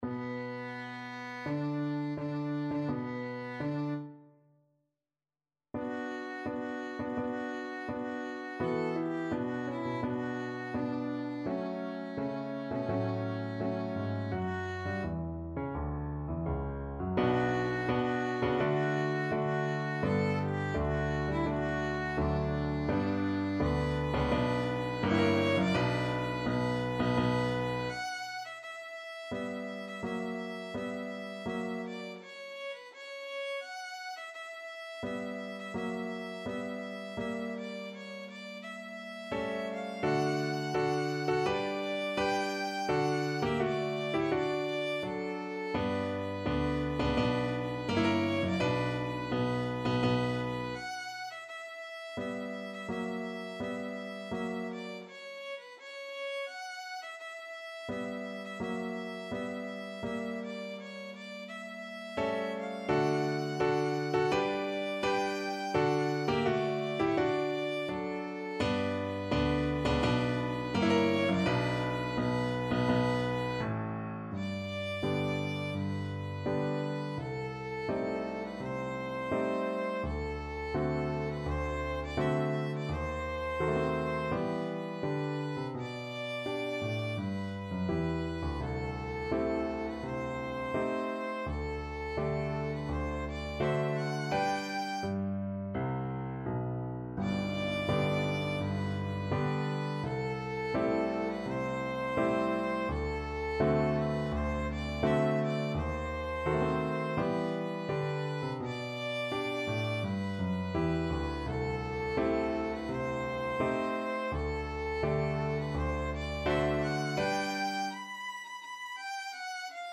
Violin
Tempo di Marcia =84
D major (Sounding Pitch) (View more D major Music for Violin )
4/4 (View more 4/4 Music)
Classical (View more Classical Violin Music)
marche-funebre-op-72-no-2_VLN.mp3